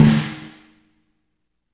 -FAT SNR1T-R.wav